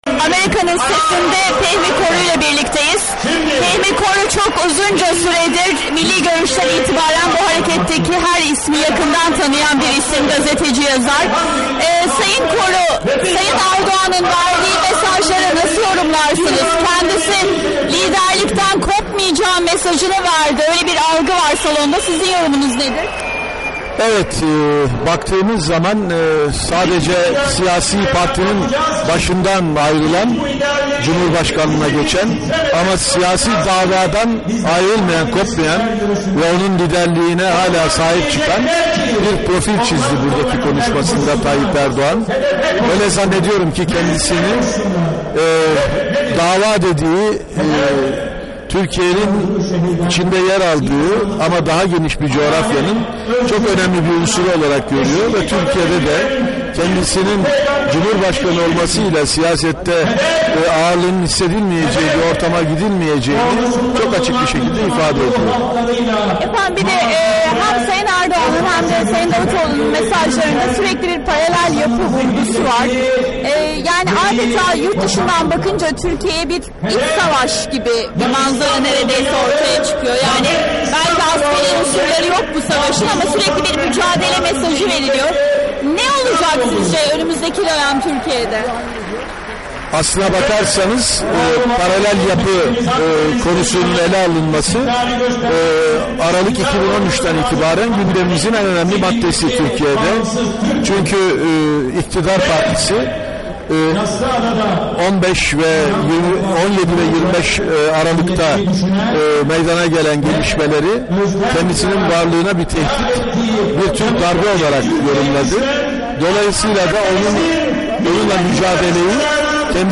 Fehmi Koru ile Söyleşi